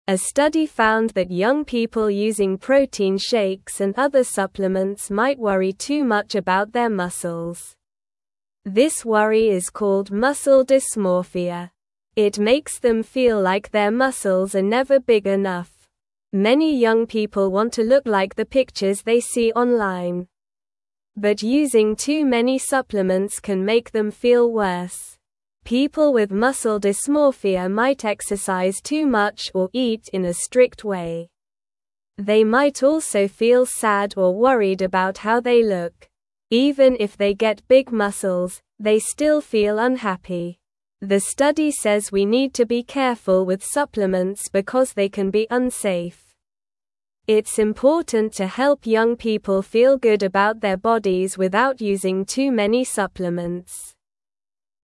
Slow
English-Newsroom-Beginner-SLOW-Reading-Worrying-About-Muscles-Can-Make-You-Unhappy.mp3